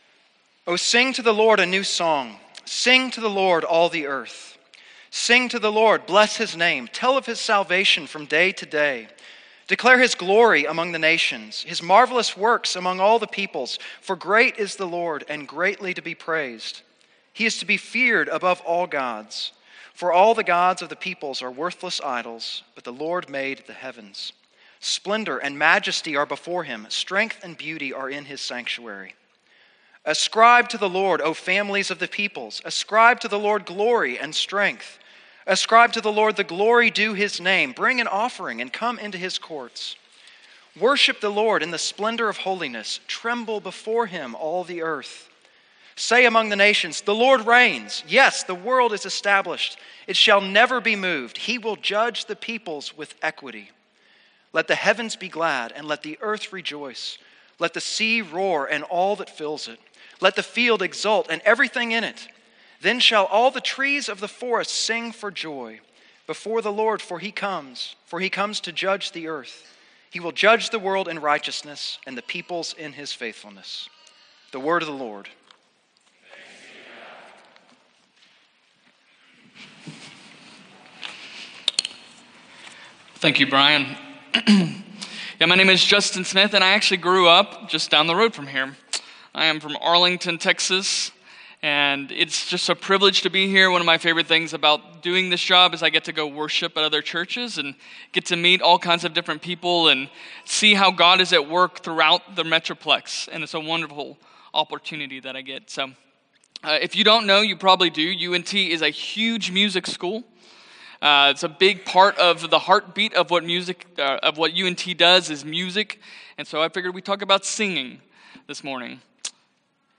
Sermons Home Sermons
Service Type: Sunday